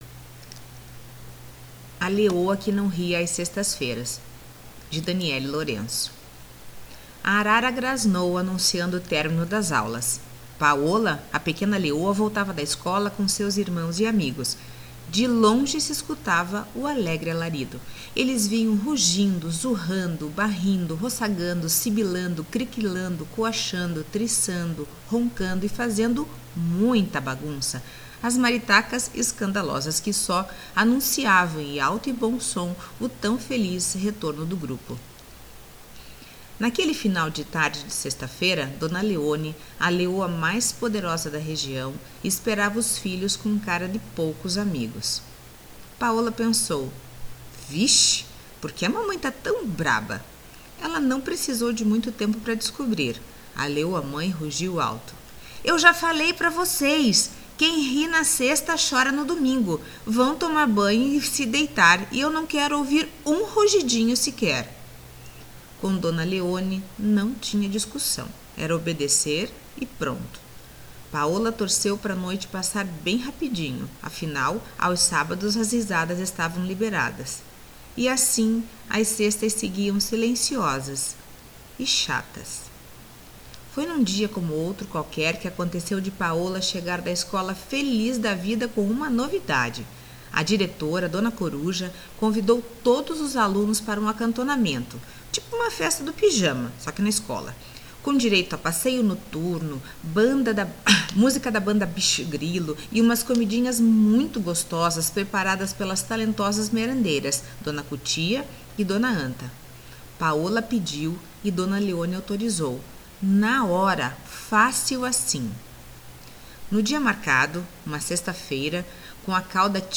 Leitura Guiada
leitura-guiada-A-LEOA-QUE-NAO-RIA-AS-SEXTAS-FEIRAS.mp3